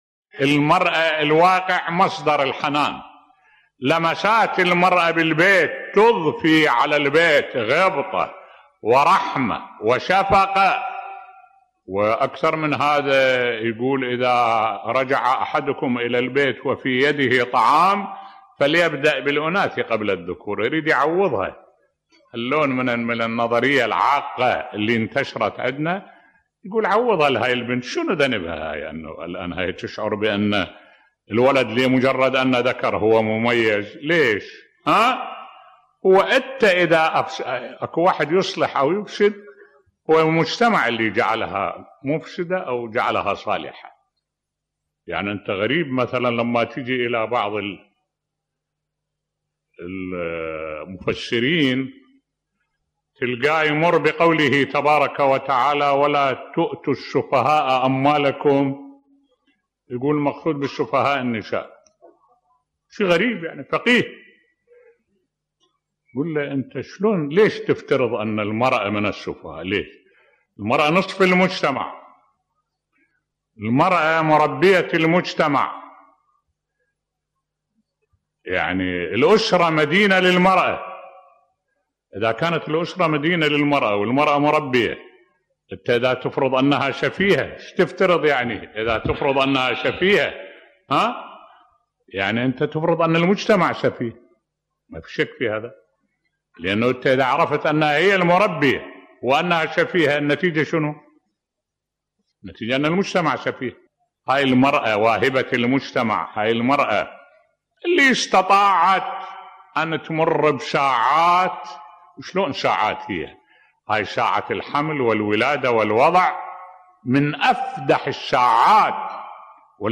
ملف صوتی حتى في اخر ايام حياته بقي يدافع عن المرأة وحقوقها بصوت الشيخ الدكتور أحمد الوائلي